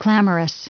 Prononciation du mot clamorous en anglais (fichier audio)
Prononciation du mot : clamorous